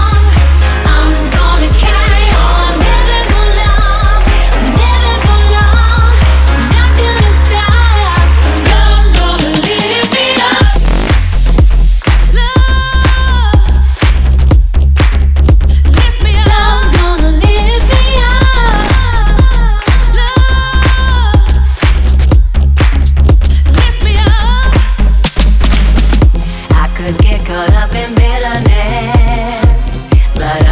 0 => "Pop"